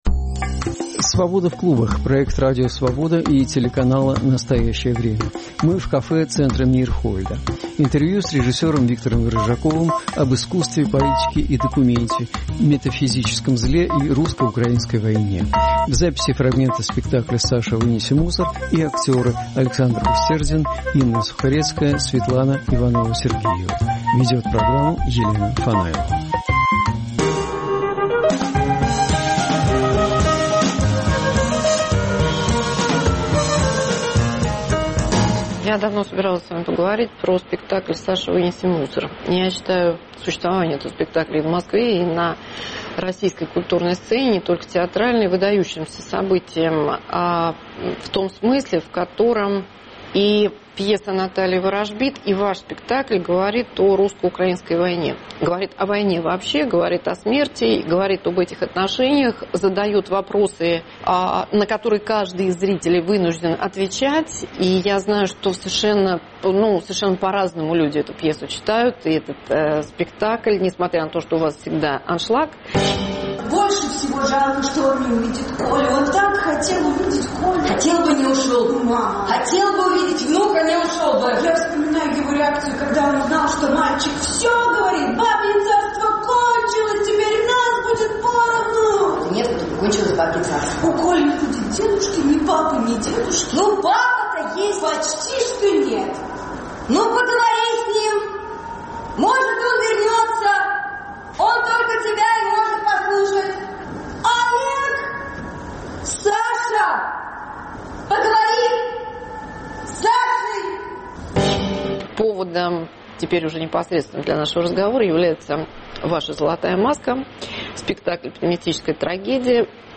Интервью с режиссёром Виктором Рыжаковым об искусстве, политике и документе, метафизическом зле и русско-украинской войне.